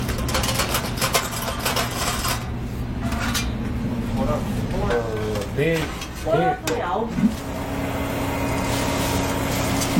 Kling-Audio-Eval / Human sounds /Human voice /audio /35860.wav